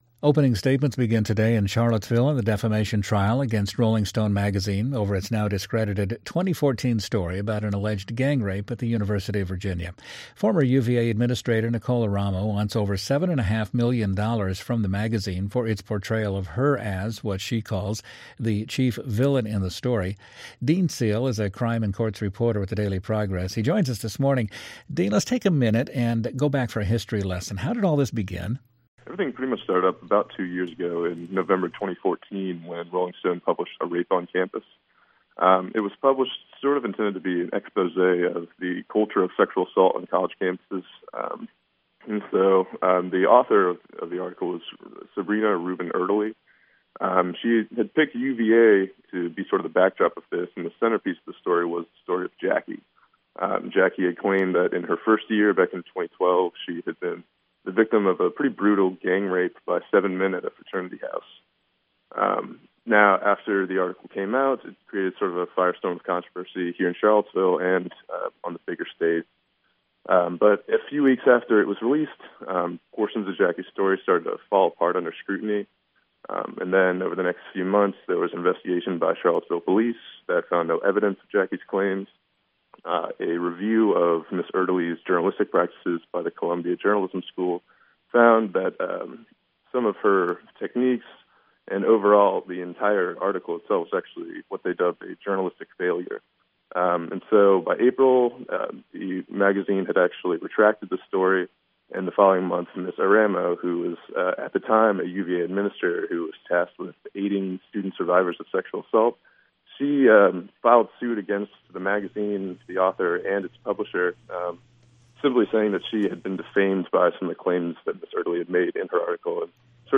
Interview Highlights